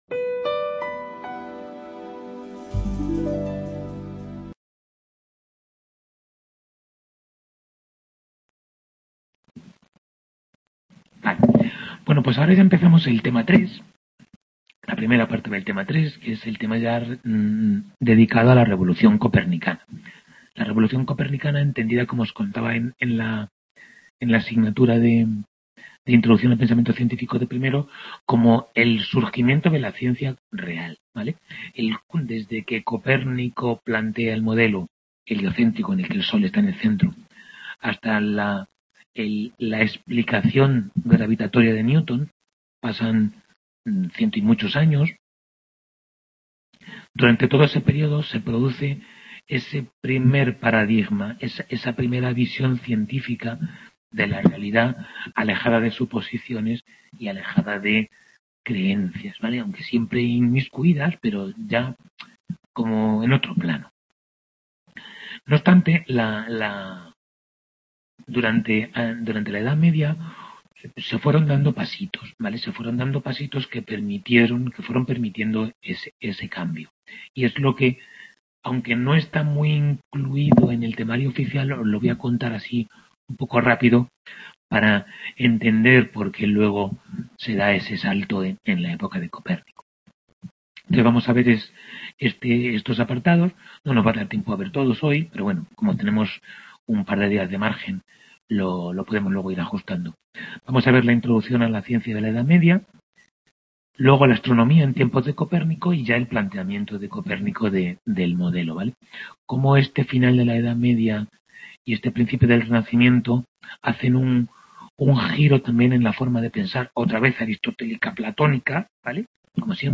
Tutoría 6b de Historia General de la Ciencia I | Repositorio Digital